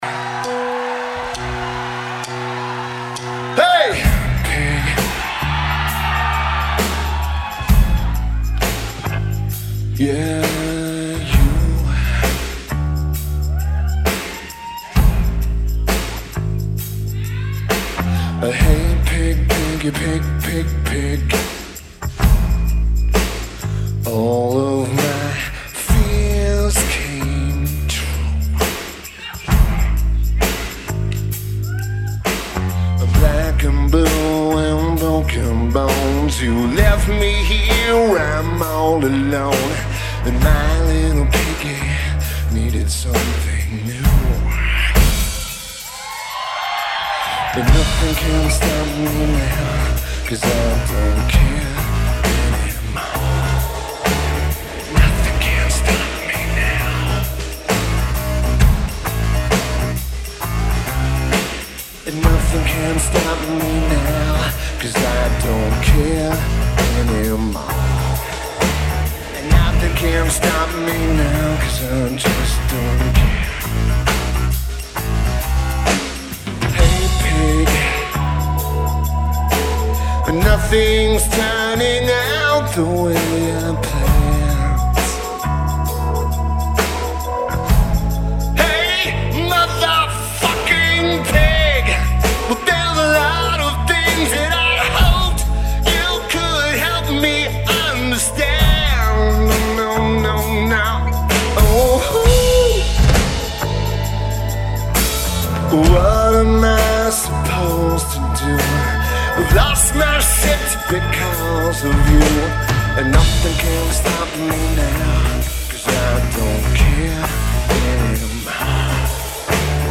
Studio Coast